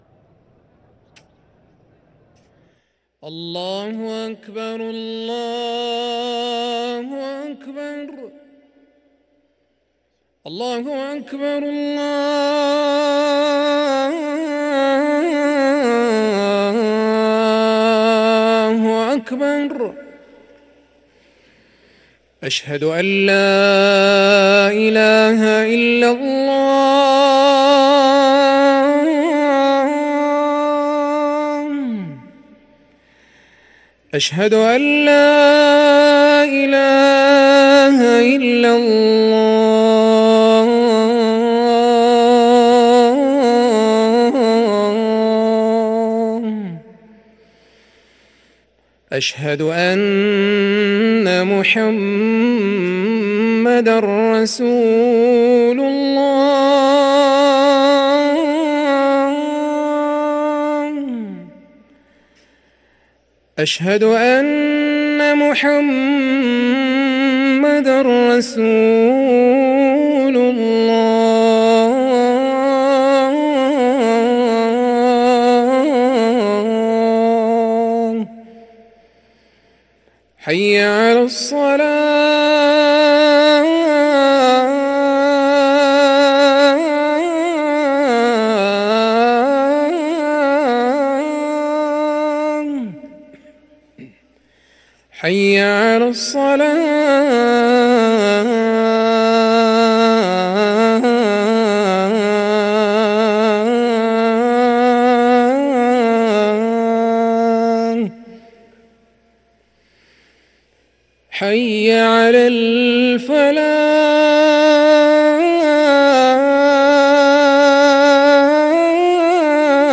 أذان المغرب